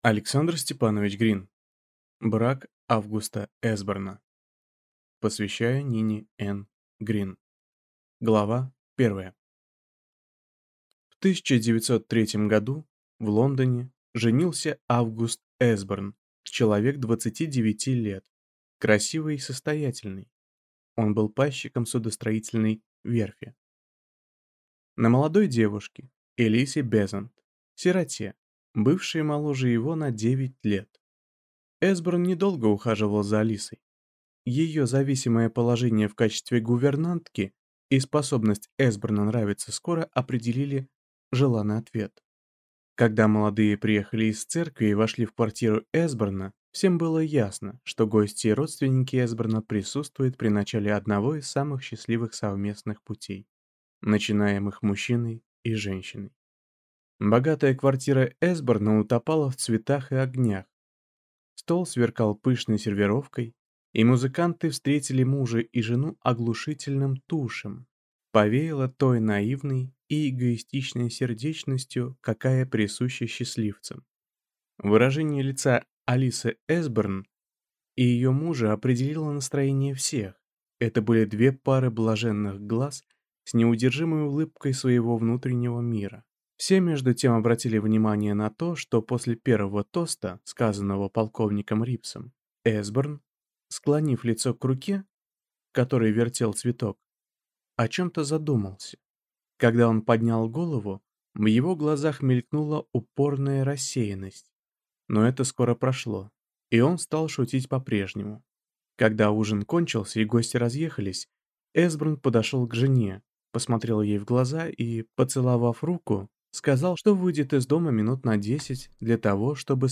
Аудиокнига Брак Августа Эсборна | Библиотека аудиокниг